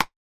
ballHit.mp3